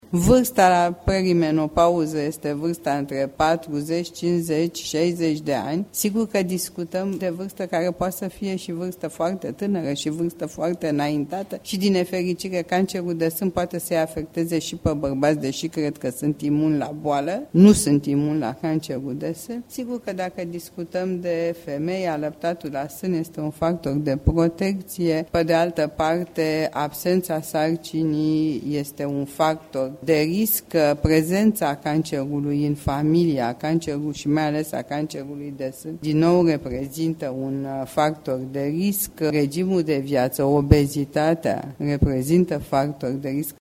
Medicul oncolog